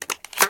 shotgunReload1.ogg